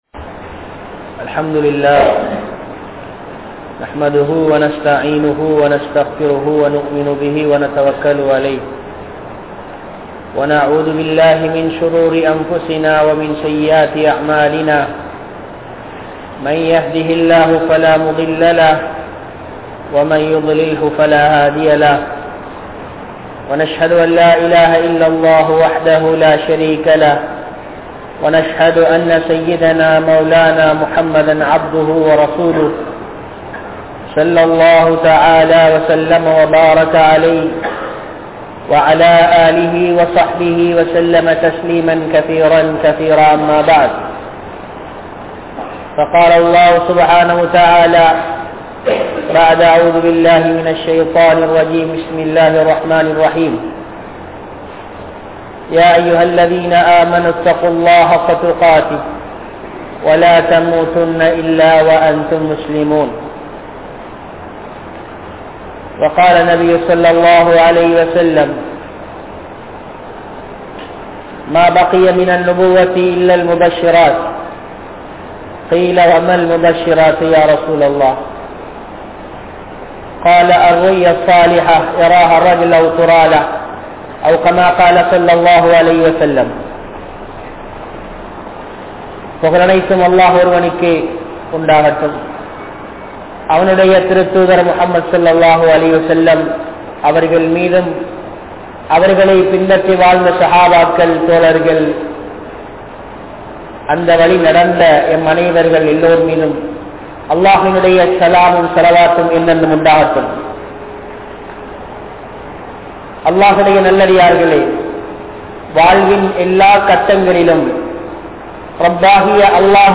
Nabi Valiel Ullathu Veattri (நபி வழியில் உள்ளது வெற்றி) | Audio Bayans | All Ceylon Muslim Youth Community | Addalaichenai